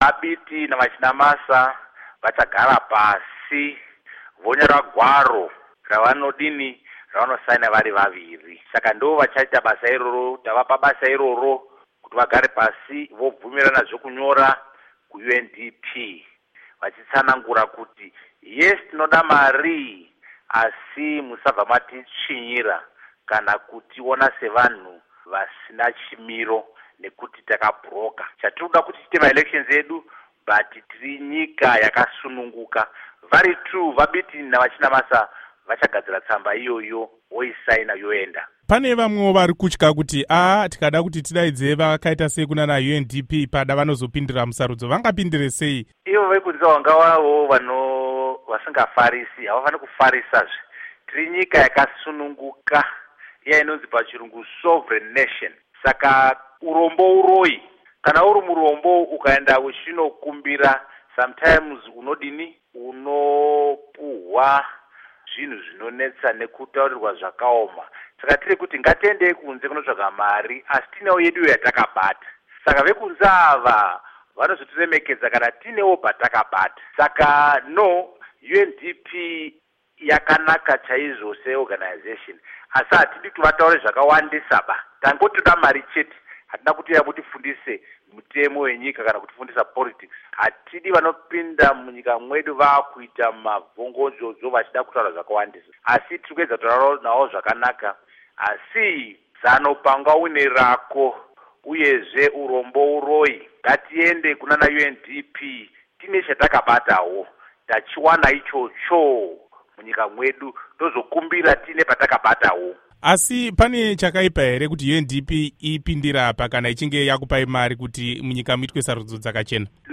Hurukuro naVaArthur Mutambara